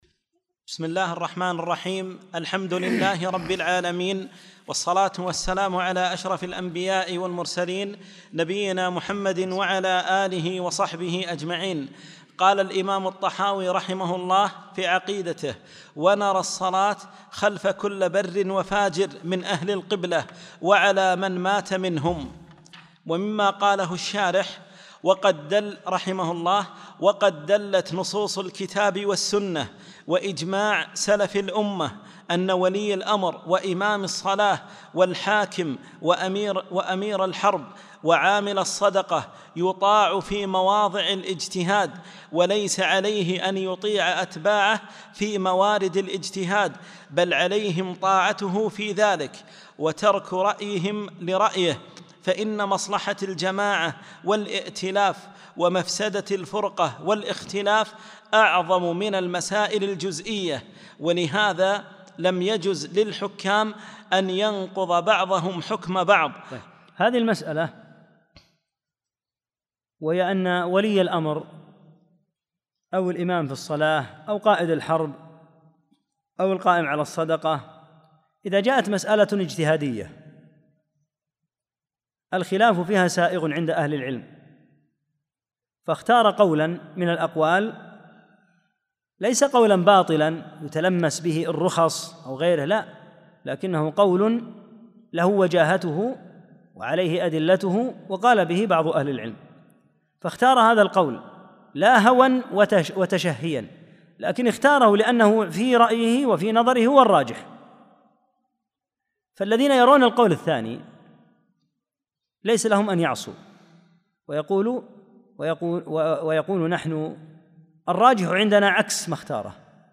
14- الدرس الرابع عشر